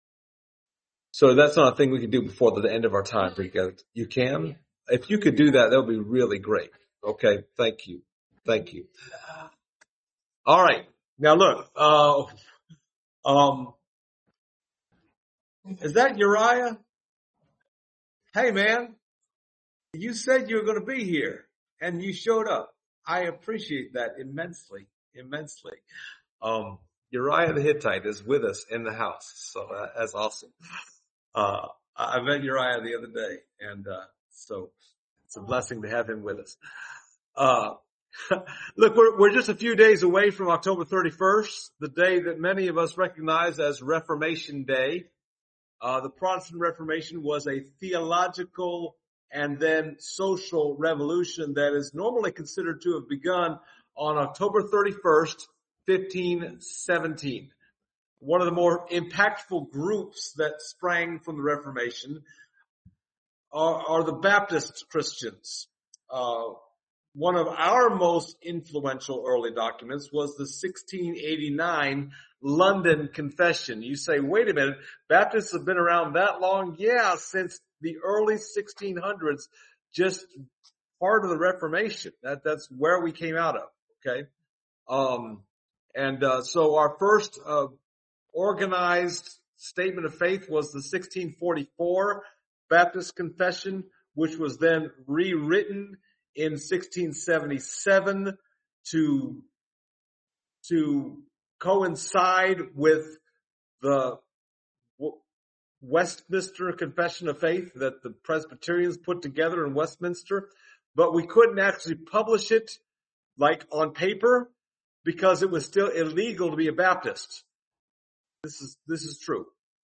Acts 26:13-18 Service Type: Sunday Morning We are just a few days away from October 31